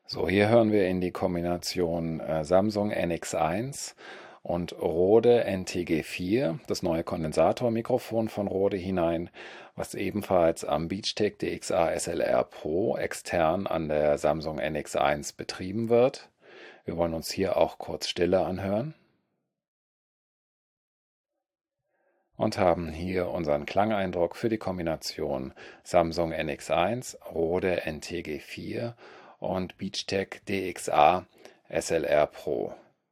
Hier inklusive Denoiser:
Samsung NX1 mit Beachtek DXA-SLR PRO und Rode NTG4 (Kondensator Richtmikro Batteriebetrieb)
SamsungNX1_NTG4Rode_norm_denoise.wav